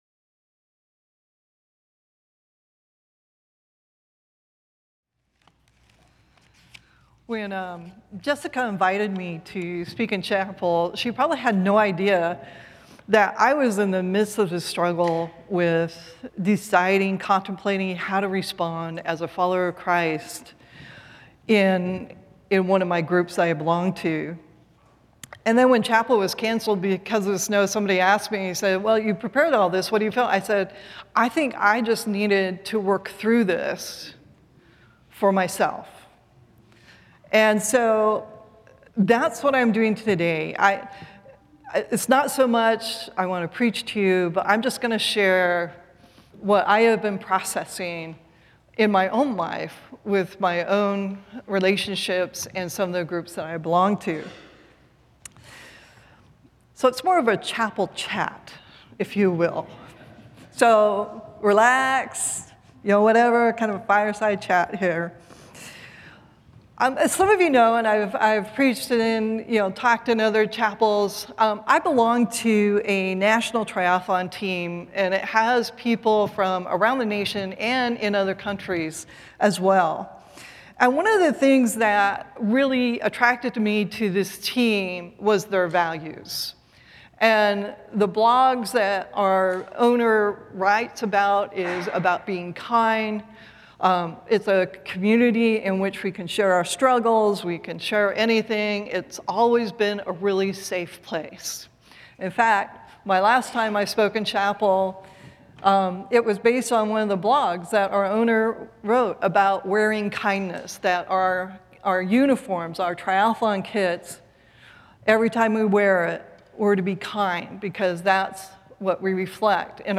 The following service took place on Thursday, March 13, 2025.